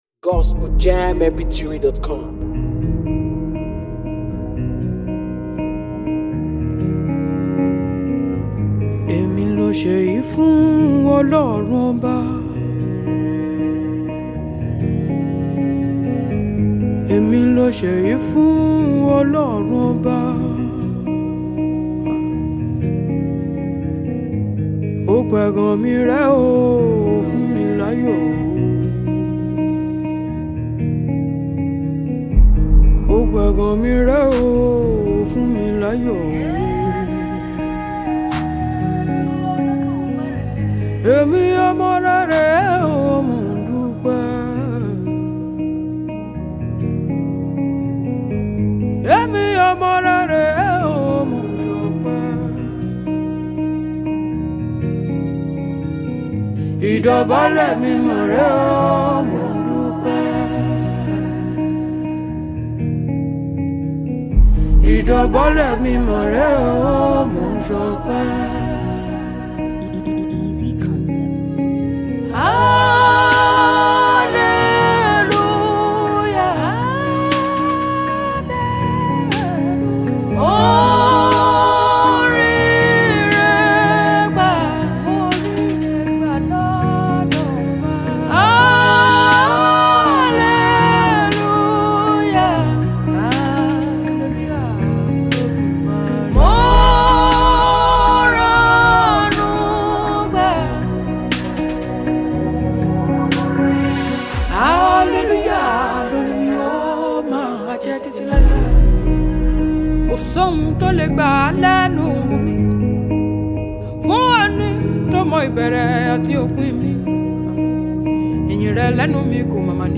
See the lastest song by talented Nigerian gospel singer